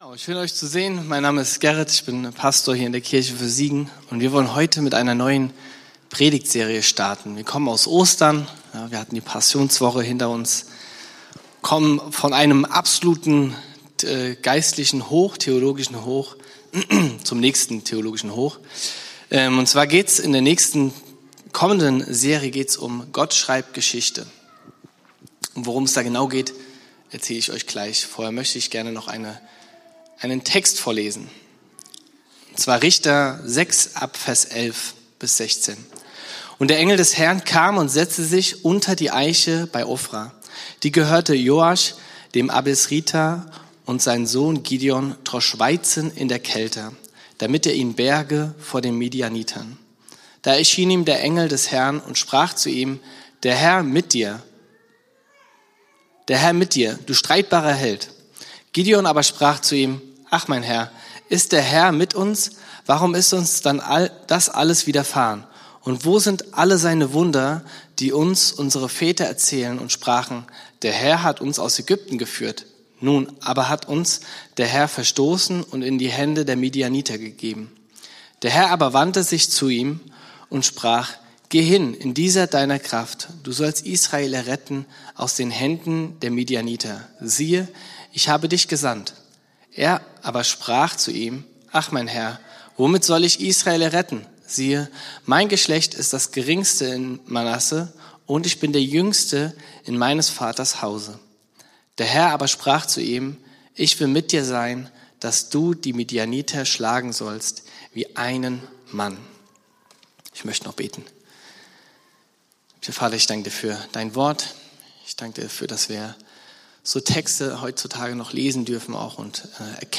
Der erste Teil der Predigtserie "Gott schreibt Geschichte".
Predigt vom 12.04.2026 in der Kirche für Siegen